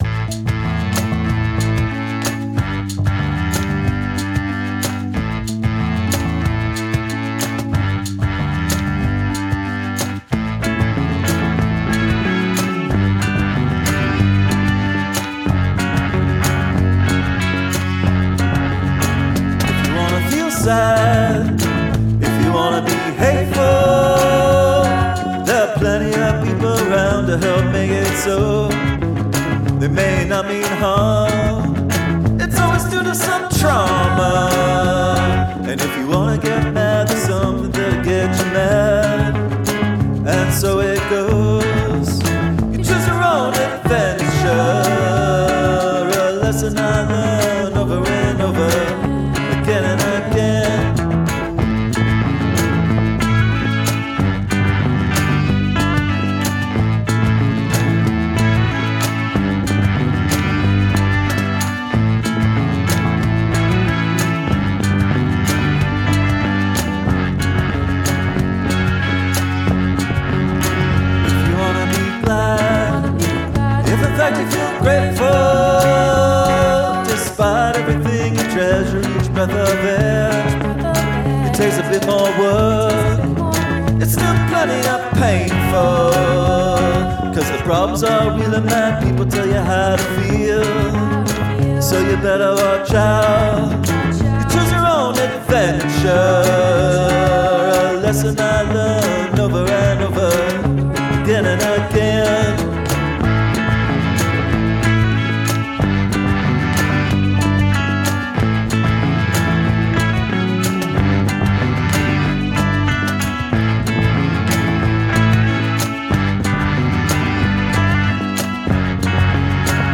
Ska or Glitch (not both)